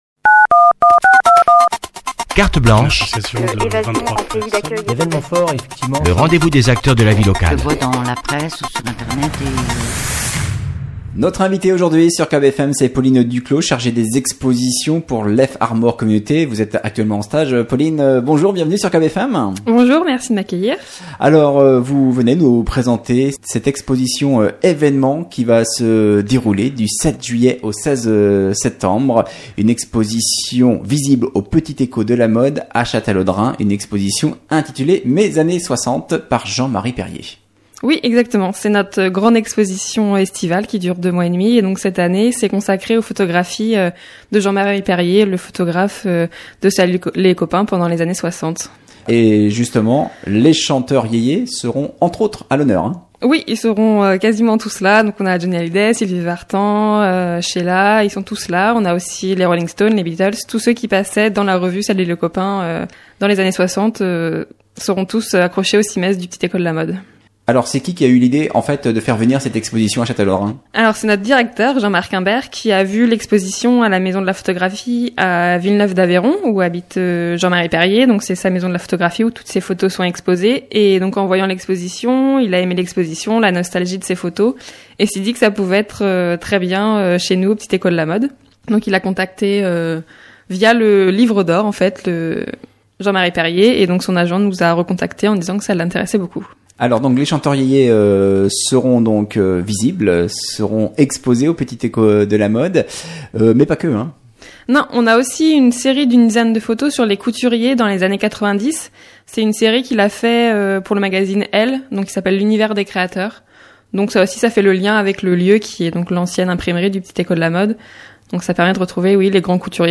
Invité de la rédaction ce lundi